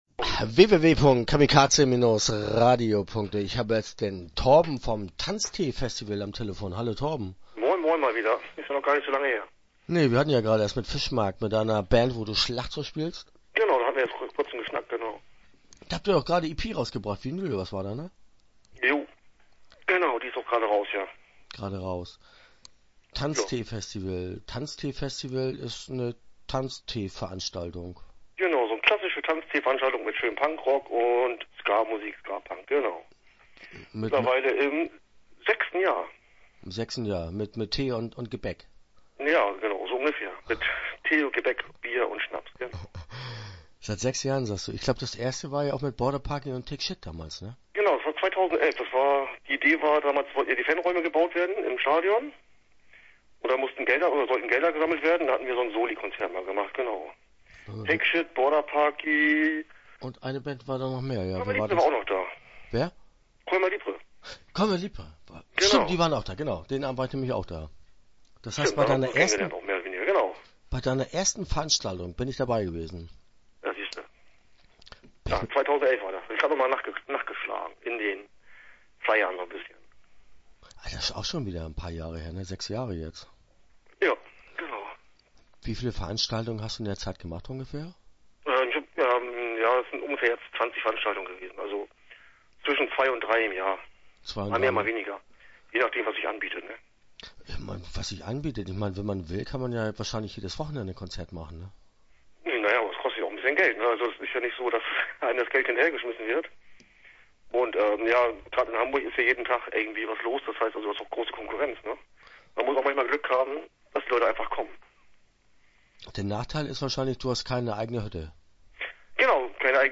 Start » Interviews » Tanztee-Festival